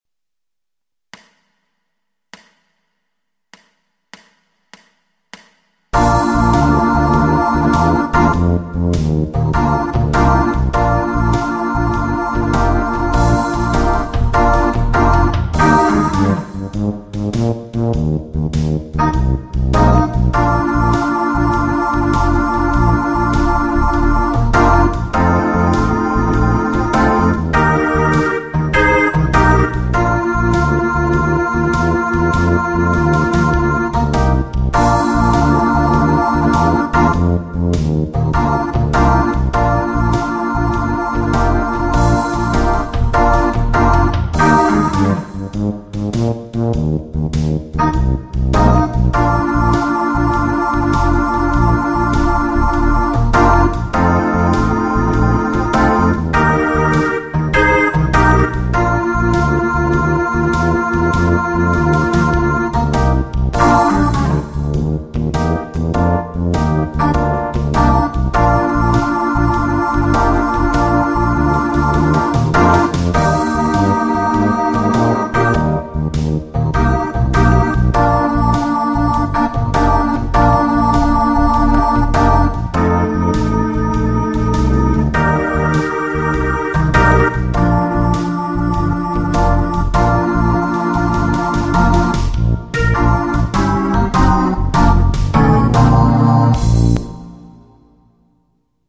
Basic Blues in Bb - 3x.mp3